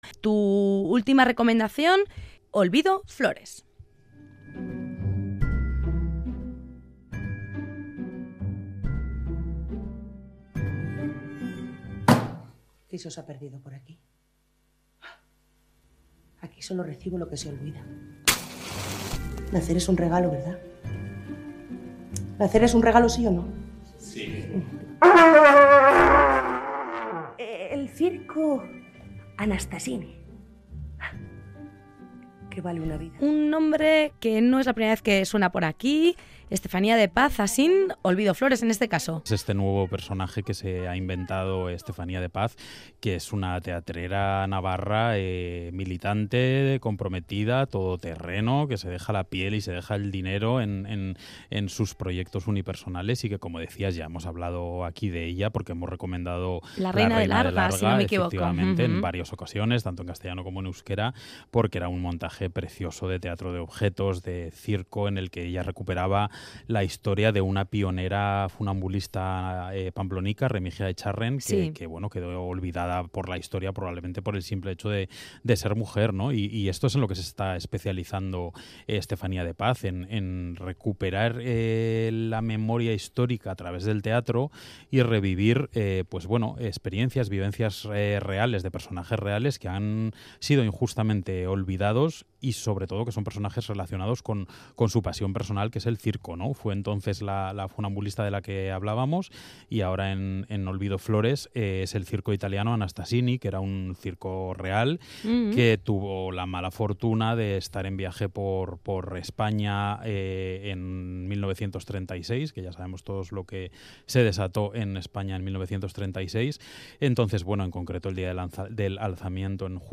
critica-olvido-flores-radio-euskadi.mp3